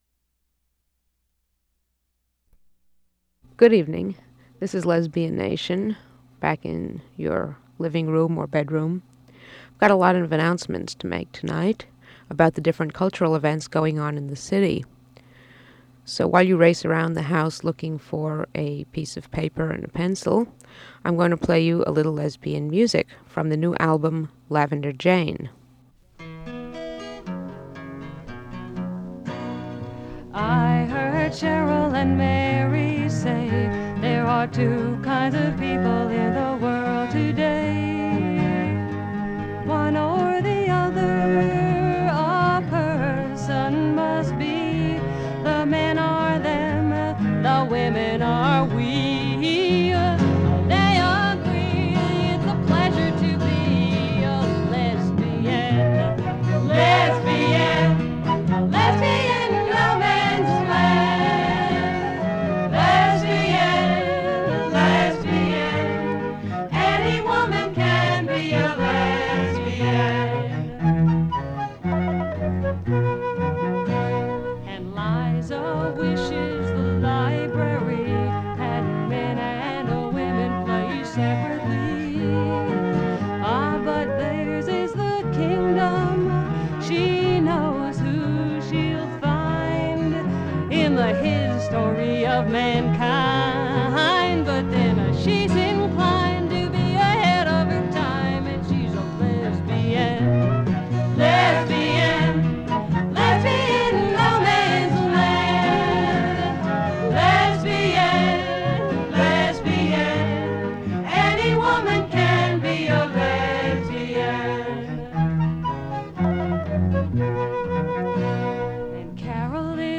Physical Format 1/4 inch audio tape Collection Lesbian Nation radio program